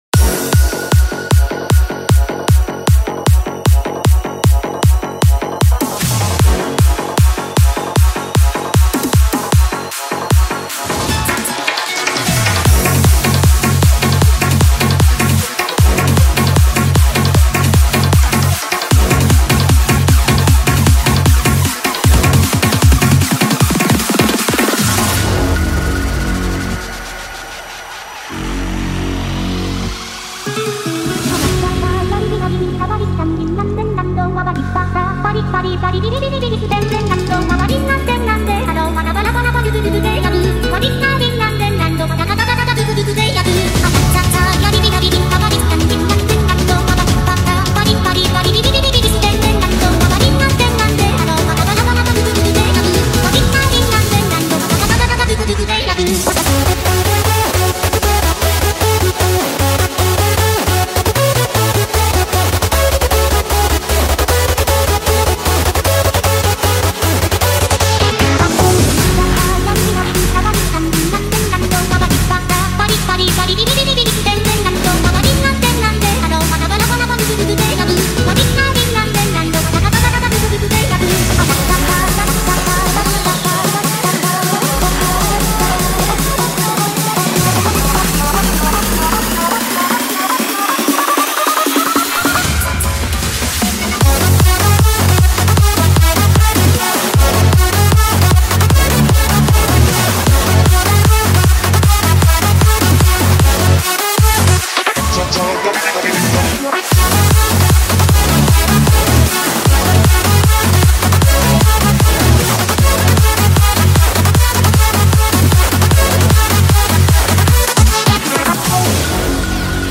polka.mp3